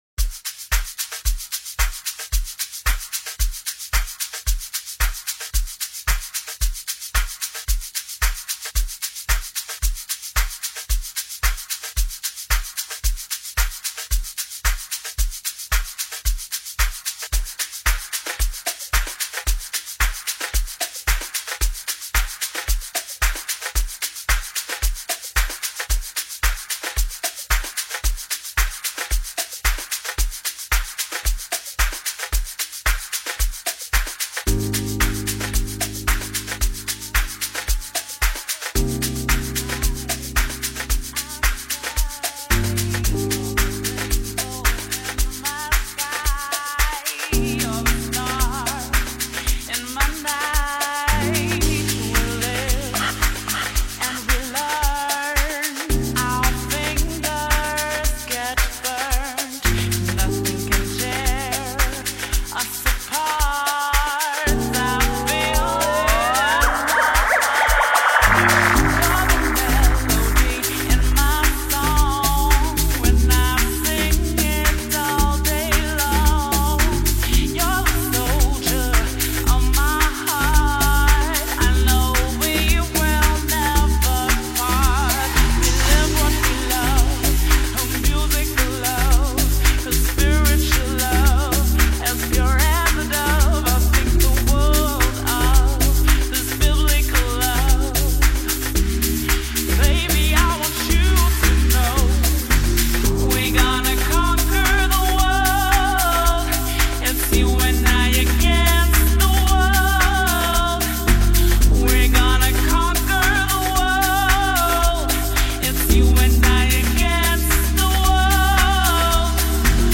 addictive Soulful drop
Soulful piano